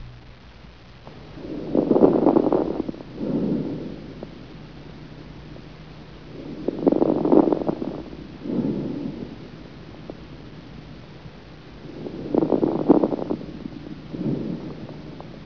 Listen to the lung sounds and try to identify with adventitious breath sounds (Table 32-3 on page 853) and we will discuss in class.
lungsound5.aif